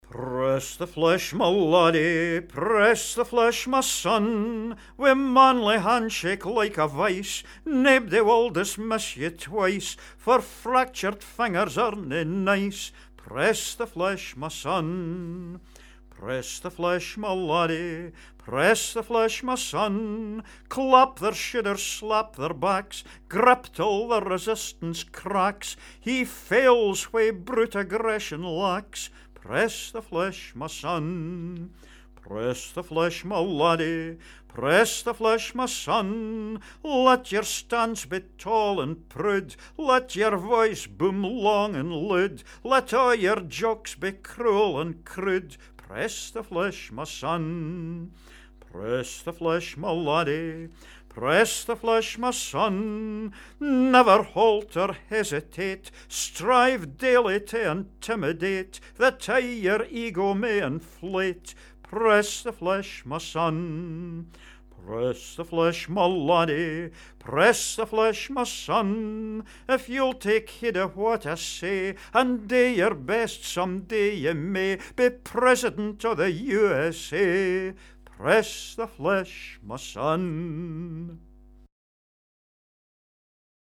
Scots Song